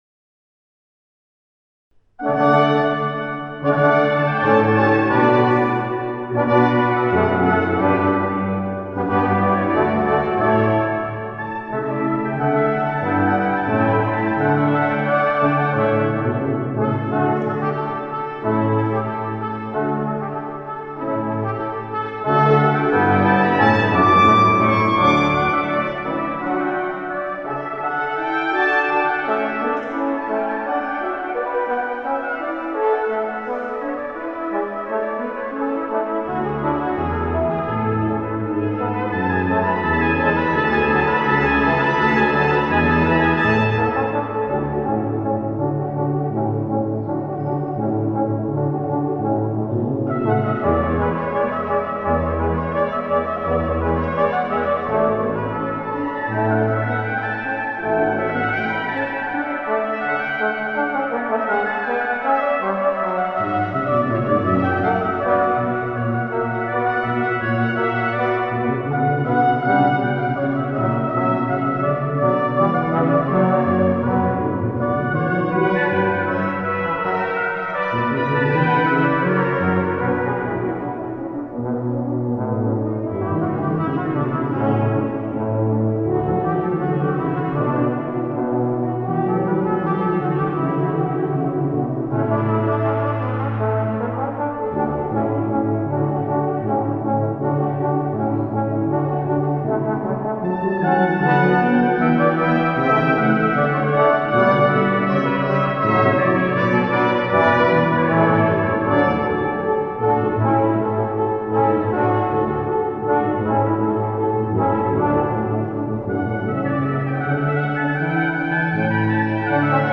Sinfonieorchester Ludwigsburg
Singakademie Stuttgart e.V
Pauluskirche Stuttgart Zuffenhausen